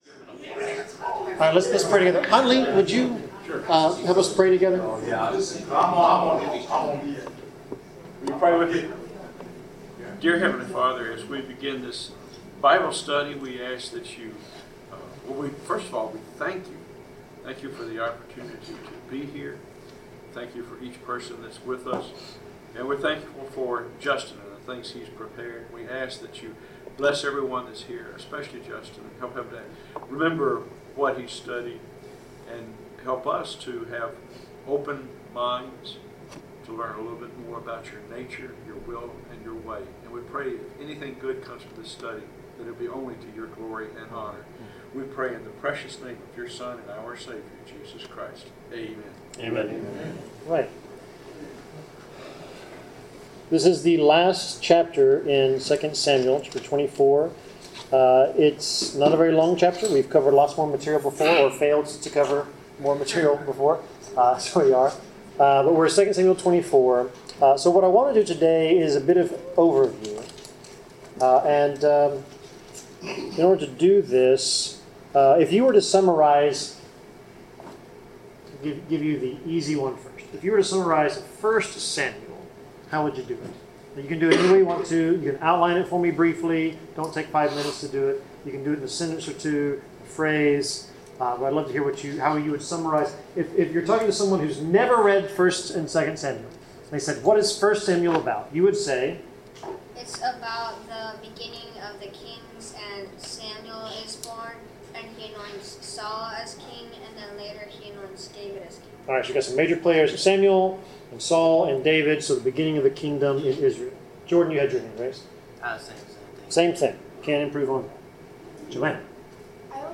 Bible class: 2 Samuel 24 (David’s Foolishness, God’s Mercy)
Service Type: Bible Class